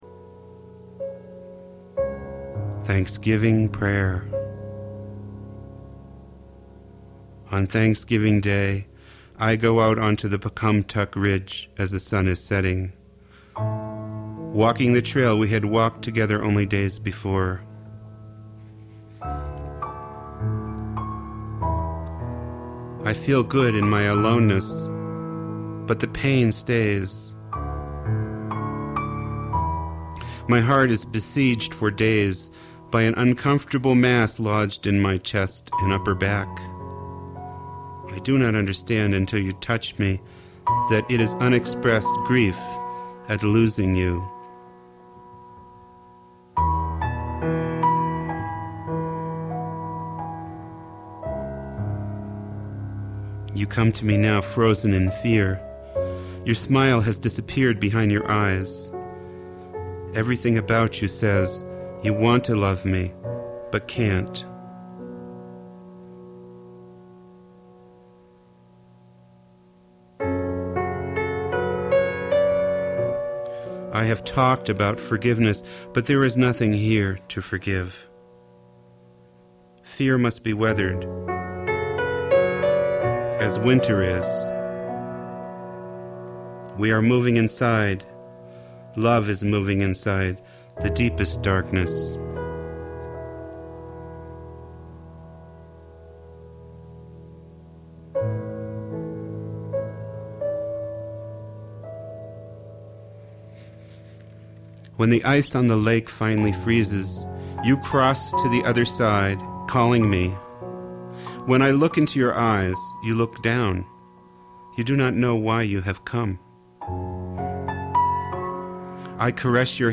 gorgeous piano accompaniment